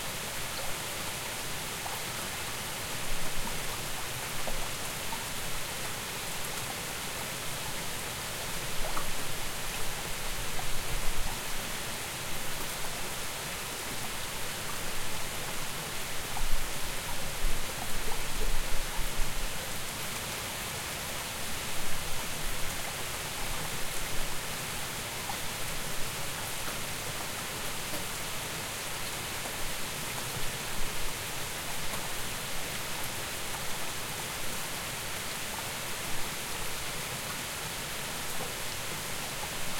sounds_rain_light.ogg